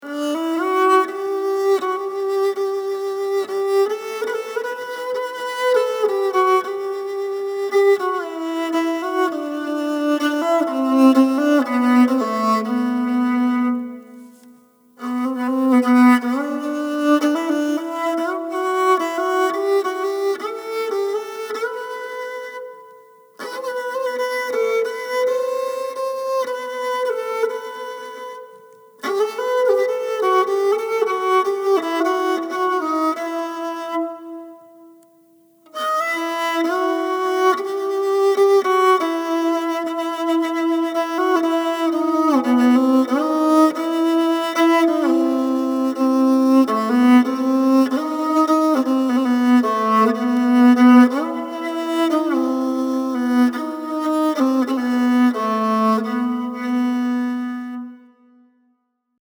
Rebab
Rebab: Instrument d’arc que proporciona un dolç i agradable sò gràcies a les seves cordes de pèls de cavall i a la caixa harmònica de closca de coco sobre la qual hi ha tensada una pell de cabra. El seu sò recorda el del violoncel i convida a la relaxació, treu l’ansietat i també la depressió.
Taksim-Rebab.mp3